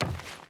Footsteps / Wood / Wood Run 1.wav
Wood Run 1.wav